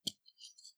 BottleFoley6.wav